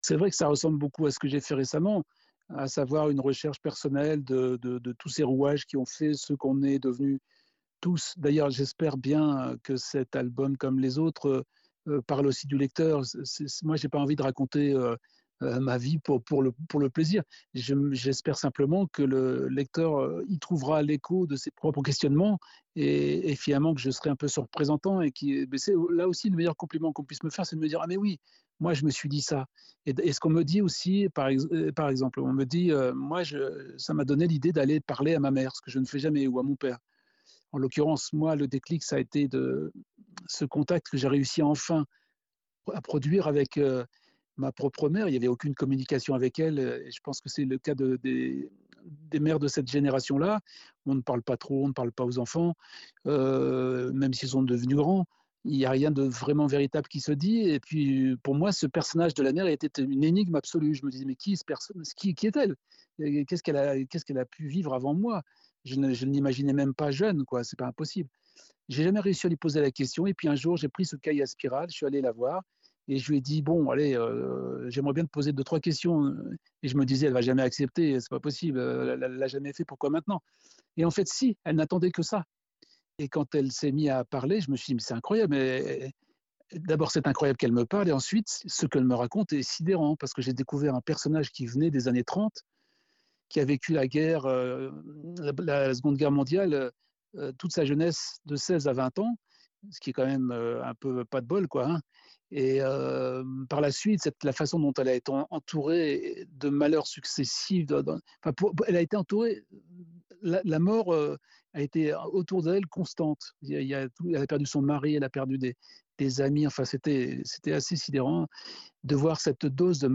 Entretien avec Didier Tronchet